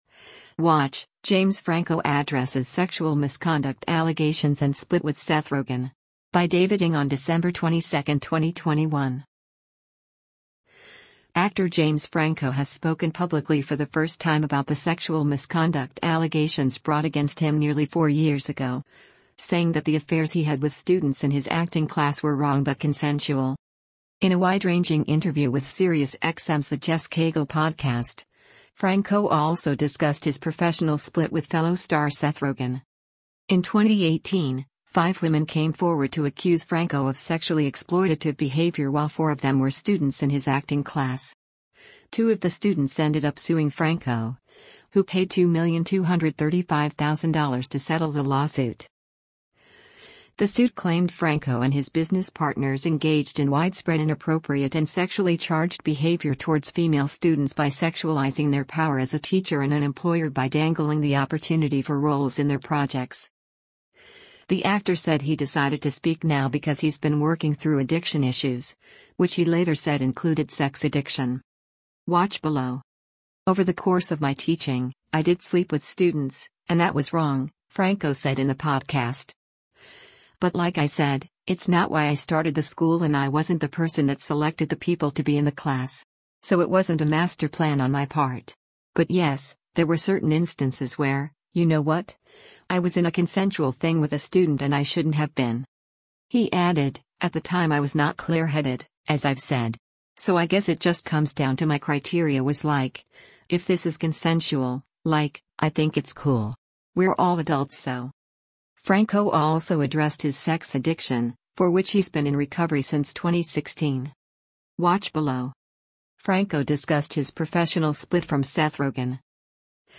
In a wide-ranging interview with SiriusXM’s “The Jess Cagle” podcast, Franco also discussed his professional split with fellow star Seth Rogen.